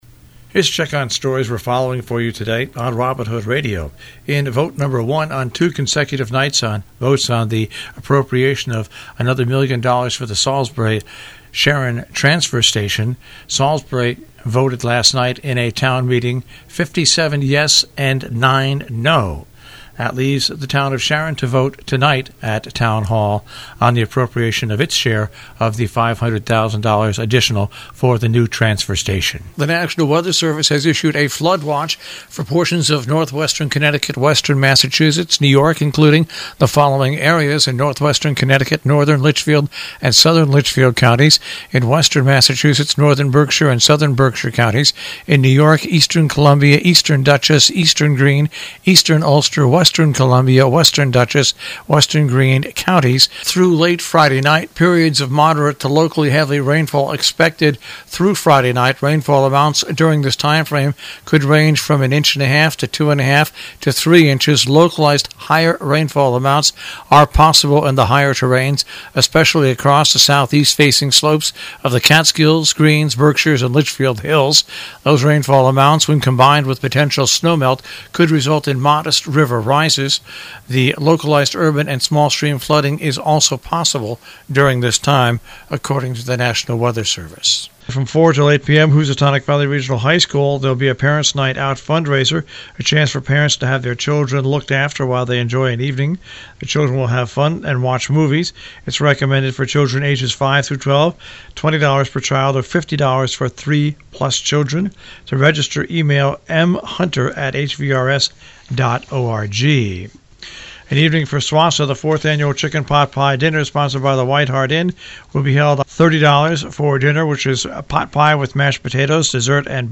WHDD Breakfast Club News